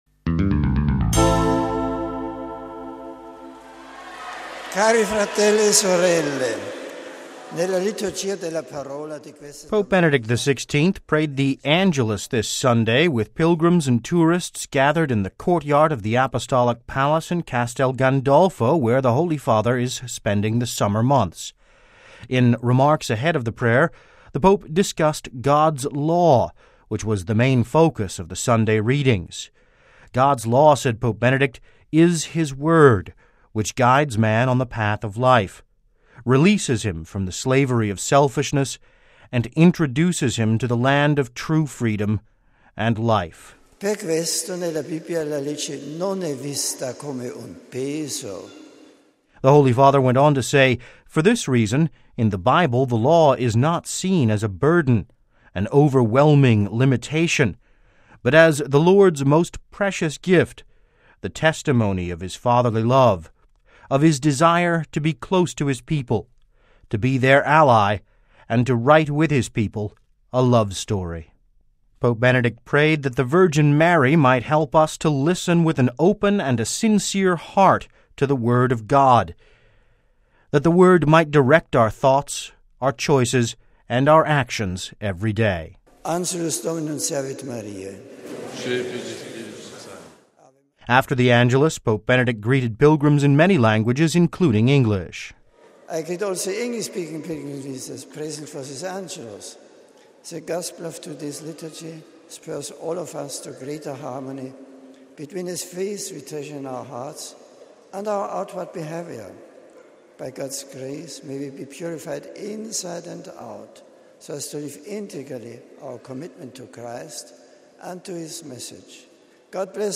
(Vatican Radio) Pope Benedict XVI prayed the Angelus with pilgrims and tourists gathered in the courtyard of the Apostolic Palace in Castel Gandolfo, where the Holy Father is spending the Summer months. Listen to our report: RealAudio
After the Angelus, Pope Benedict greeted pilgrims in many languages, including English: